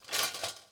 SFX_Cooking_Utensils_02_Reverb.wav